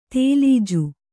♪ tēlīju